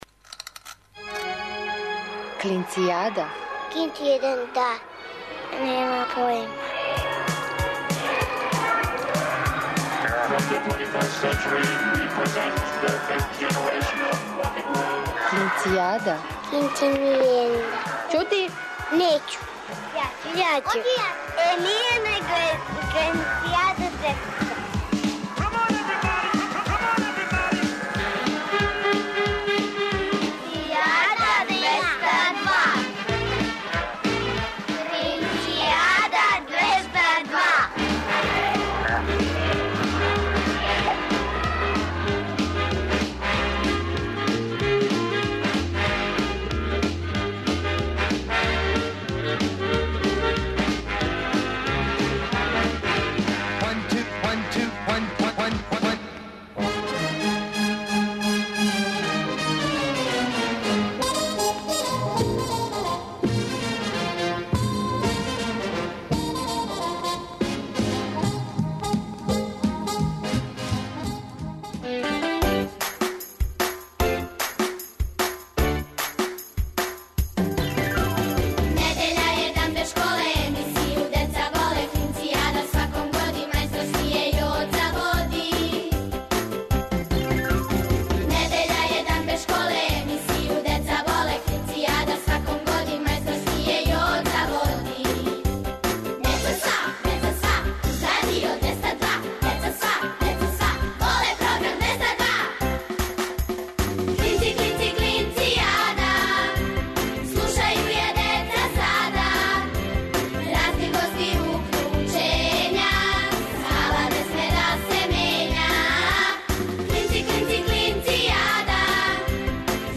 О деци за децу, емисија за клинце и клинцезе, и све оне који су у души остали деца. Сваке недеље уживајте у великим причама малих људи, бајкама, дечјим песмицама. Ако будете са нама упознаћете будуће шампионе, научнике, новинаре, музичаре, сликаре... нашу будућност.